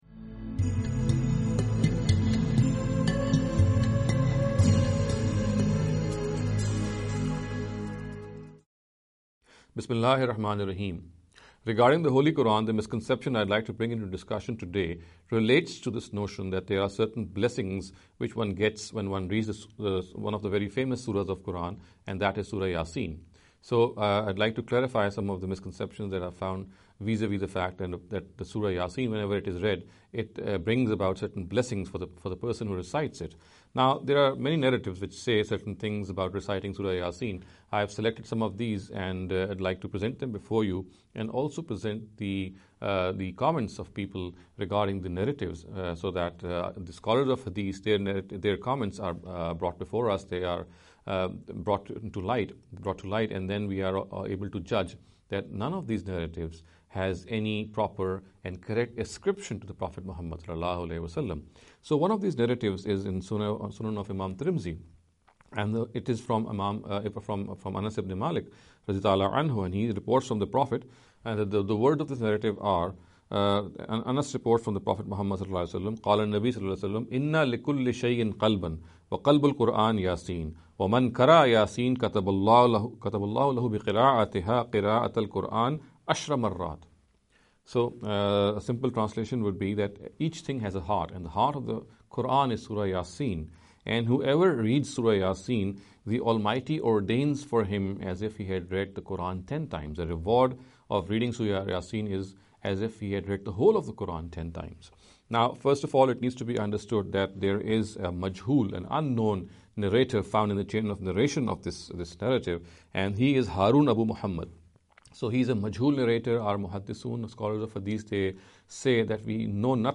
In every lecture he will be dealing with a question in a short and very concise manner.